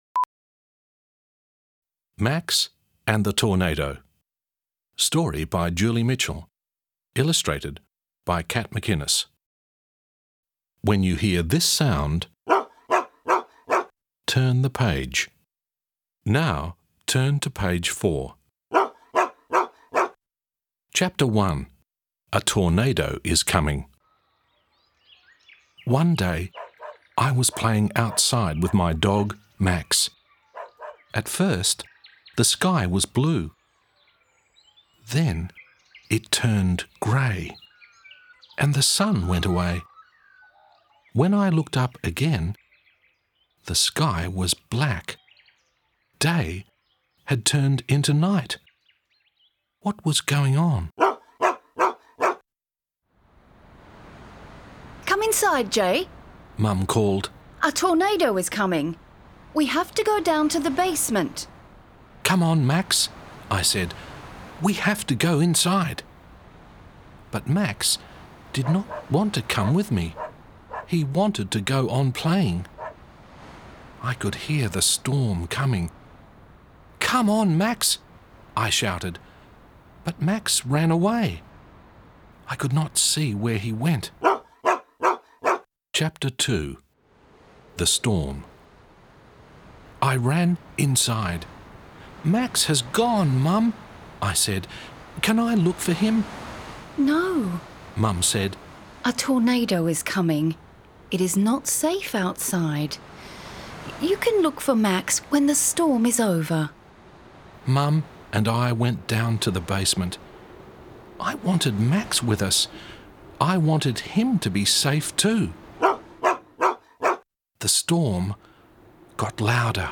Short Story